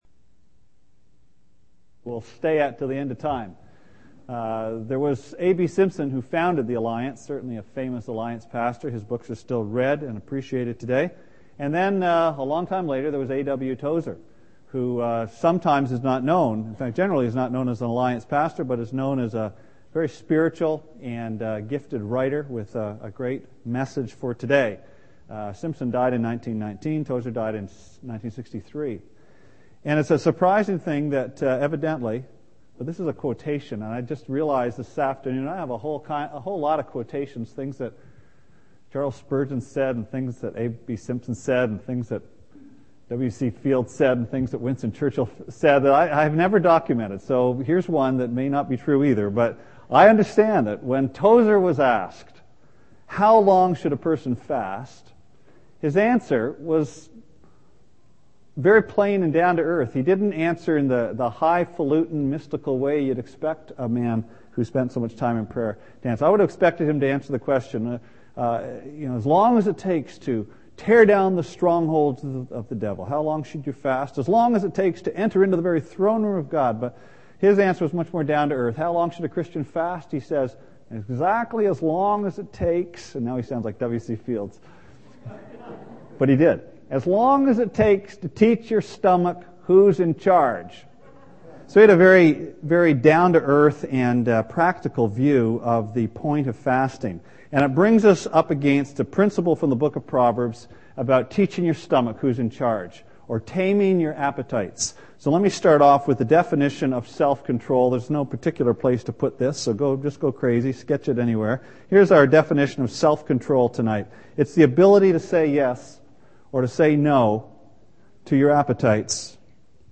Part 5 of a Sunday evening series on Proverbs.